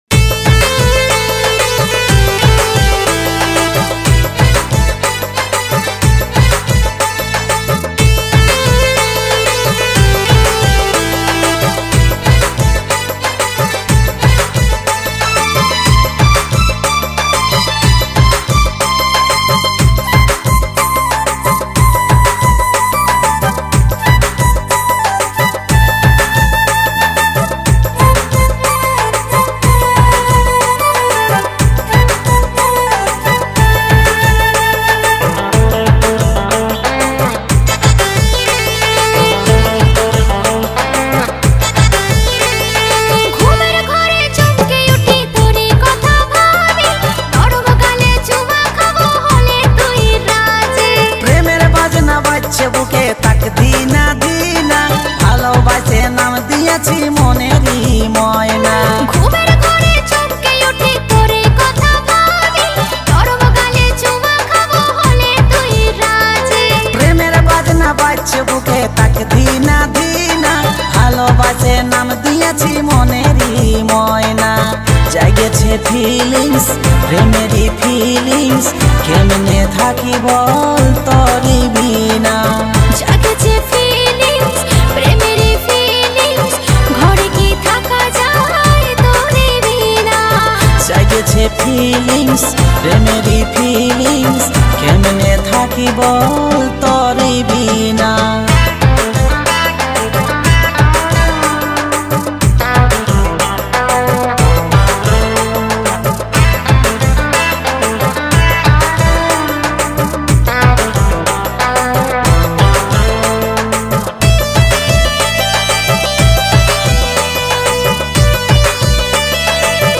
Releted Files Of Purulia Gana